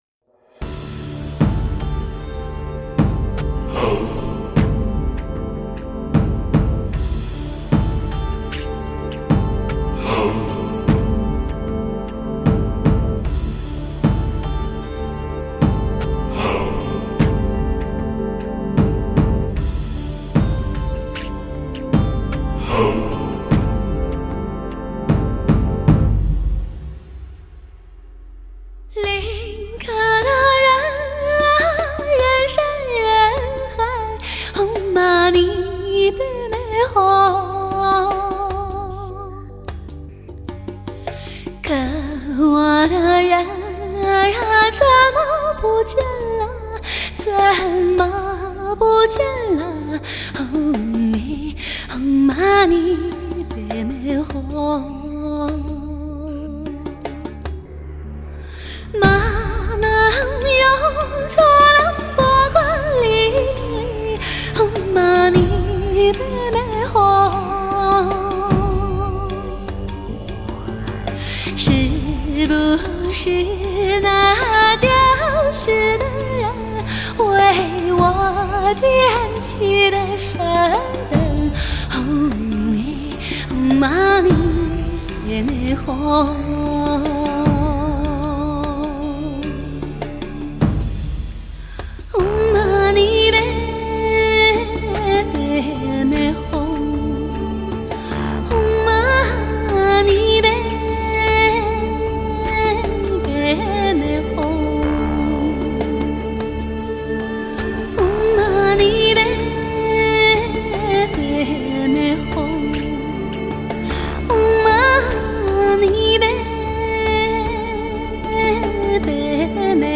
德国黑胶CD
兼容黑胶的高保真和CD的低噪音